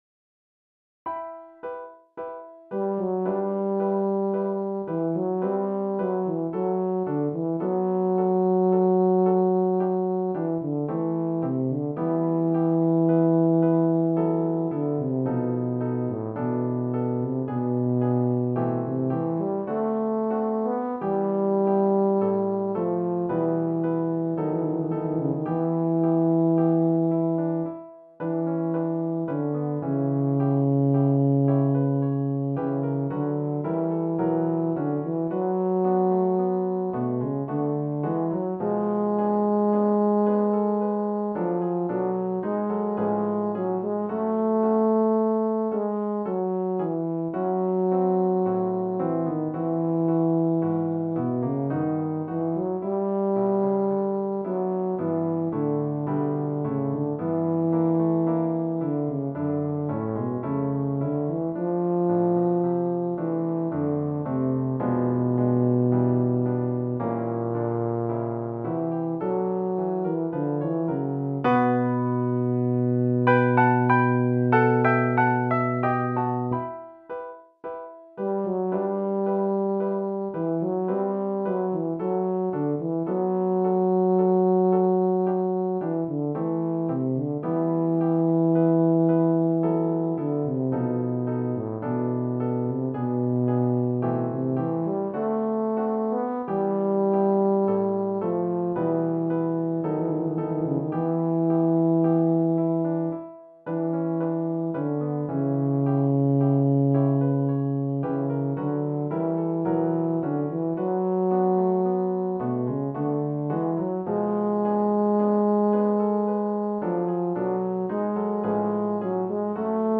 Voicing: Tuba Solo w/piano